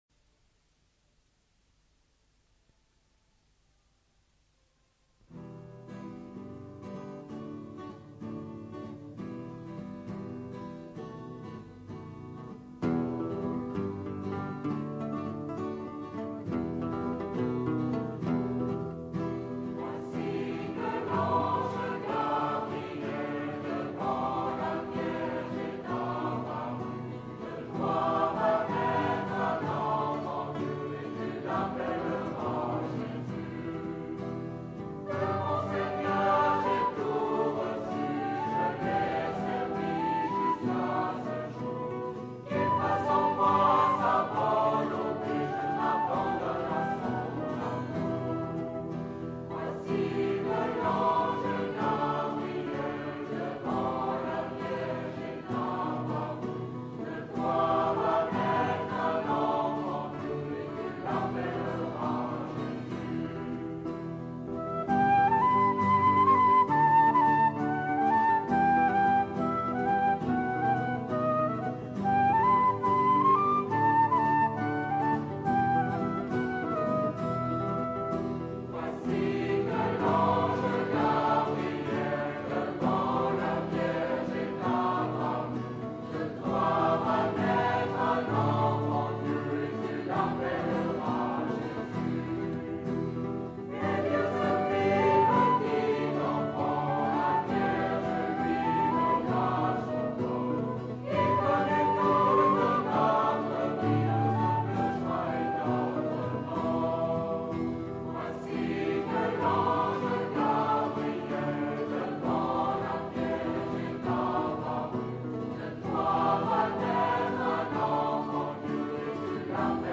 Eglise Saint-François-de-Paule Fréjus - 22e dimanche après la Pentecôte